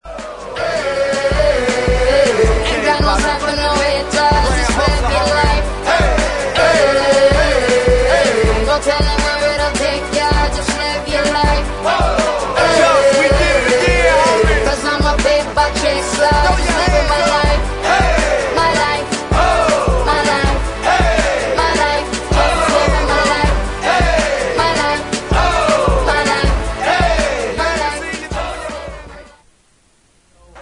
• Rap Ringtones